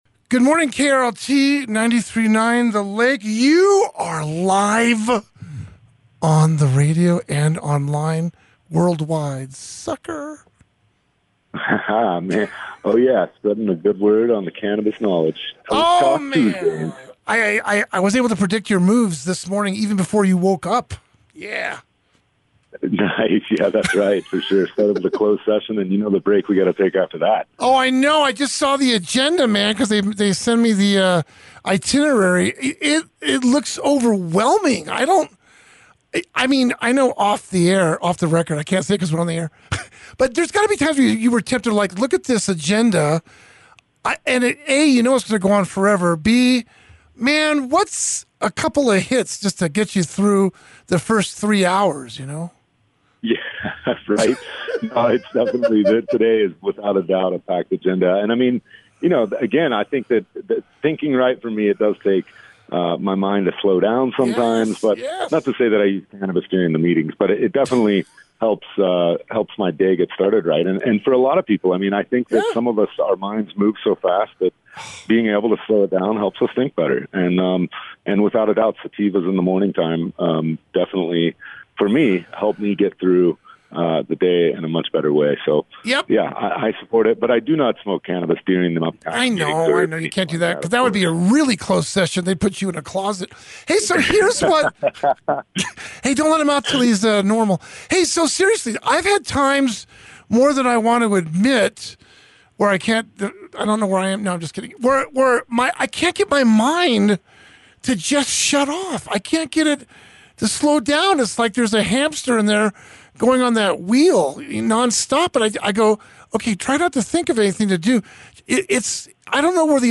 The lake You are live on the radio and online worldwide.